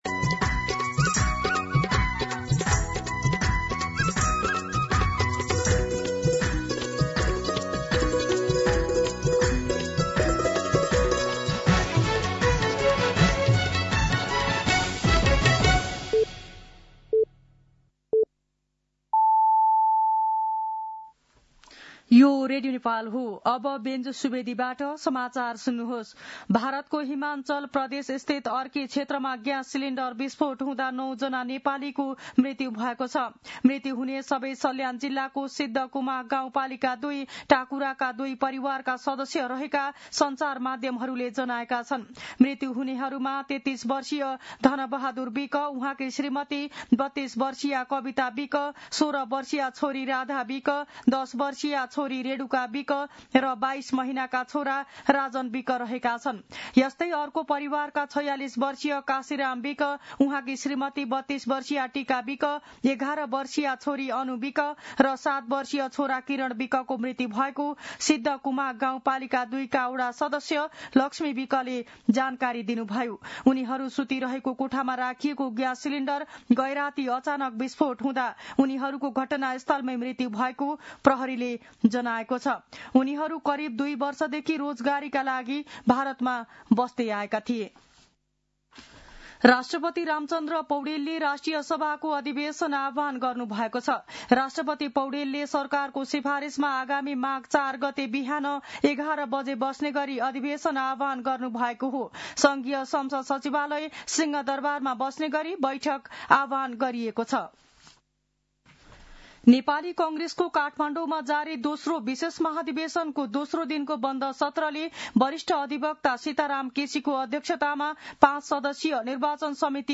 दिउँसो १ बजेको नेपाली समाचार : २९ पुष , २०८२